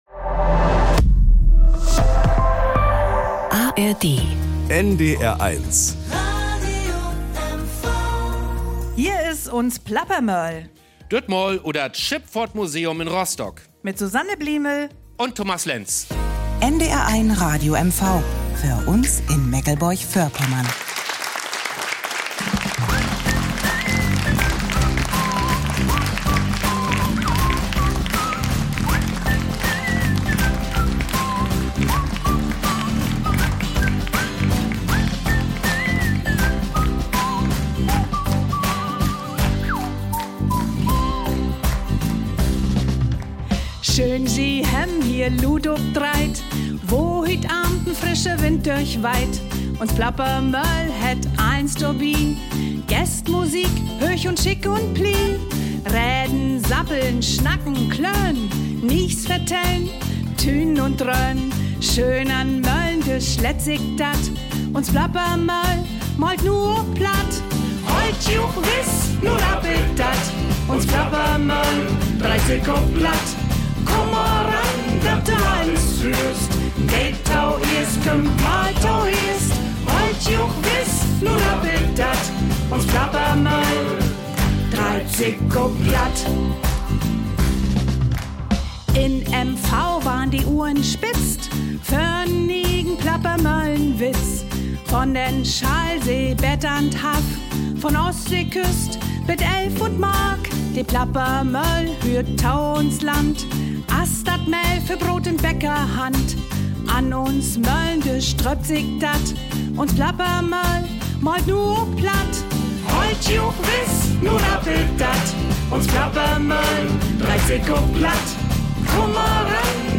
Zum ersten Mal nach mehr als 450 Sendungen ist die Plappermoehl auf einem Schiff zu Gast - auf der MS Dresden, die zugleich das Schifffahrtsmuseum der Stadt beherbergt.